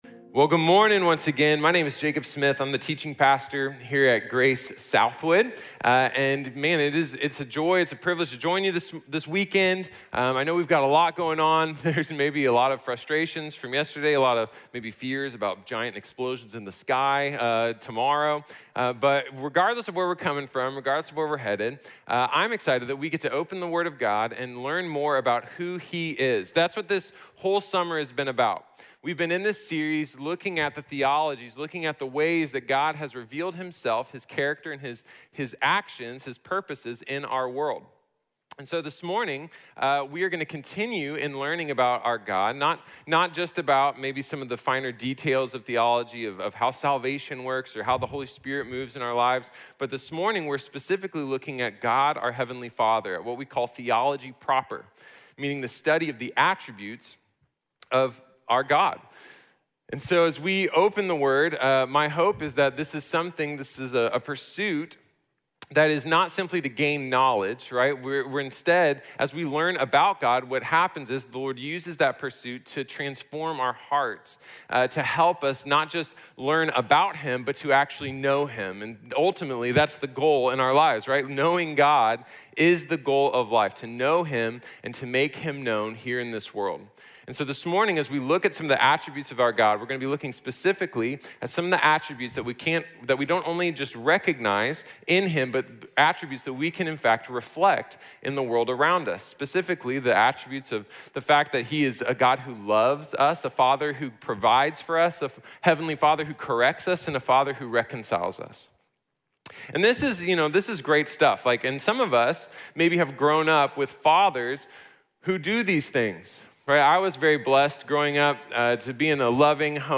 Theology Proper | Sermon | Grace Bible Church